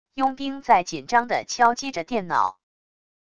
佣兵在紧张地敲击着电脑wav音频